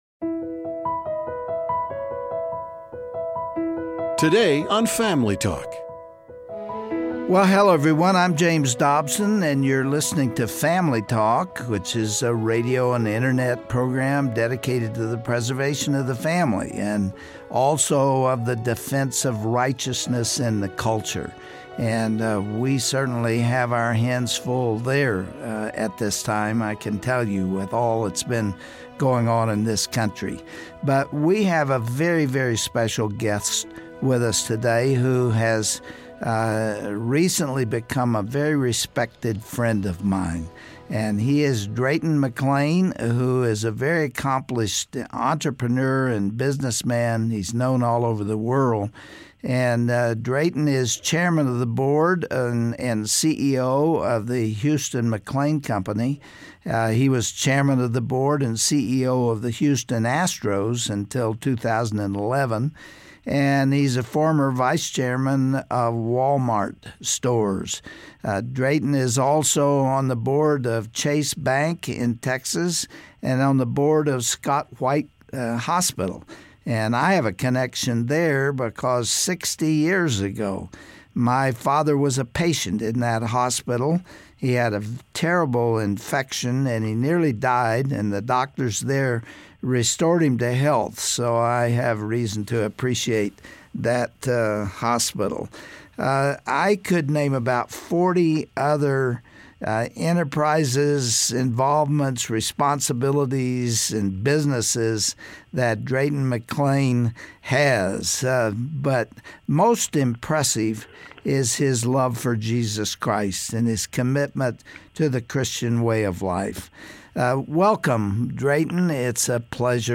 On today's edition of Family Talk, Dr. James Dobson interviews Drayton McLane about serving God through whatever doors He opens.